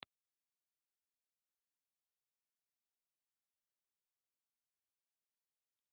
Impulse Response file of a Reslo RBM/200 ribbon microphone.
Reslo_RBM_200_IR.wav